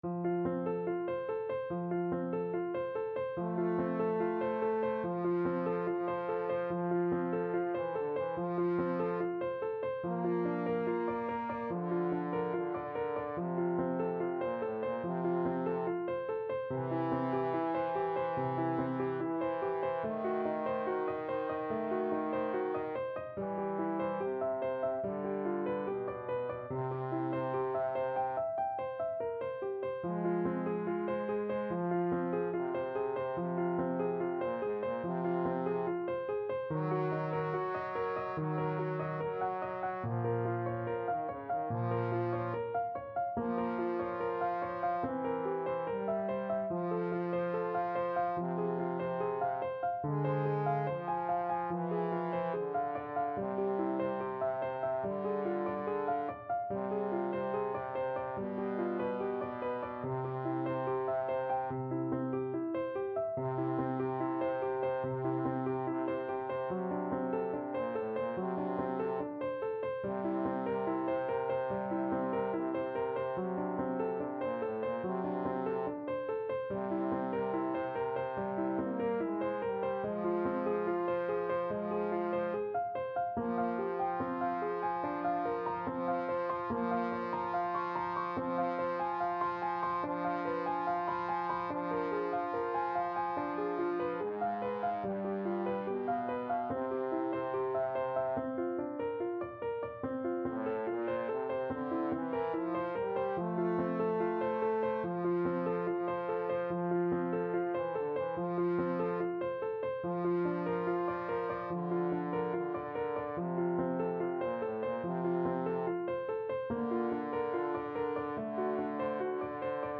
Andante =72
Trombone Duet  (View more Intermediate Trombone Duet Music)
Classical (View more Classical Trombone Duet Music)